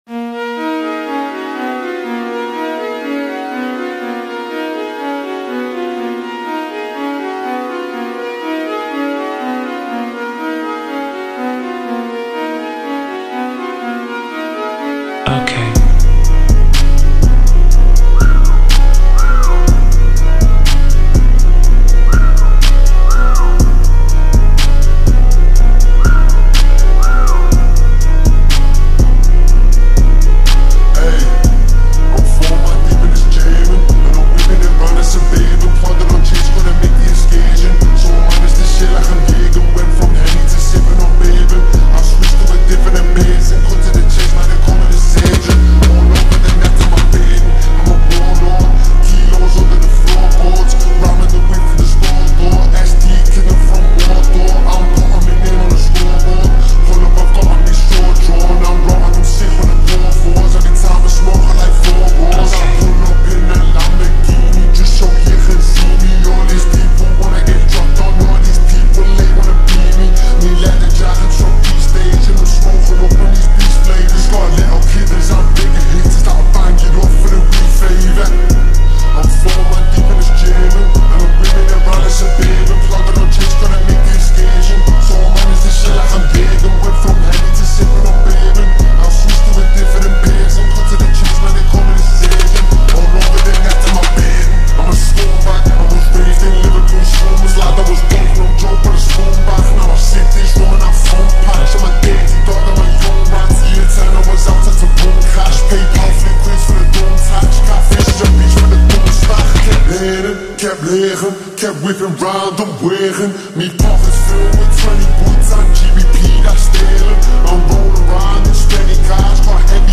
Slowed Down
Reverb
Rap
Slowed + Reverb